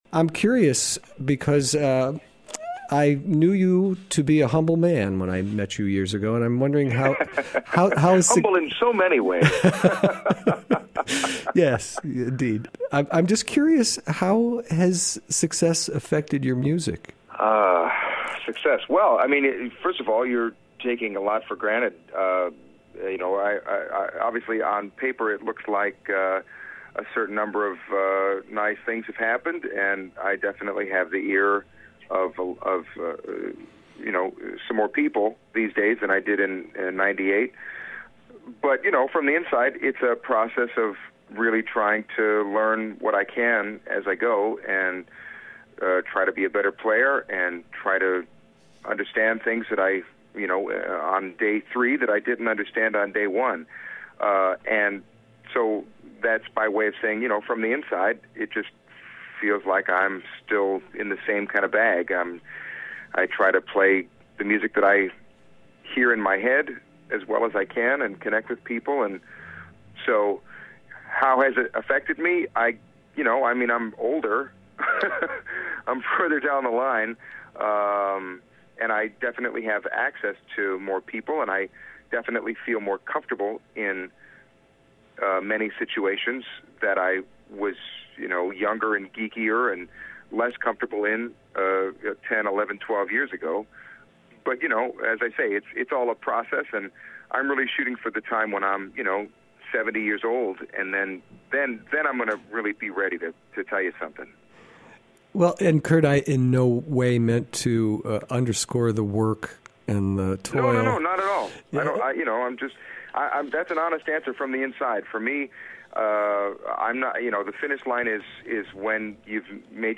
Elling Heads to Fayetteville Elling.mp3 Kurt Elling has received just about every award a jazz vocalist can be given.